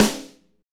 Index of /90_sSampleCDs/Northstar - Drumscapes Roland/SNR_Snares 1/SNR_Motown Snrsx
SNR MTWN 0KL.wav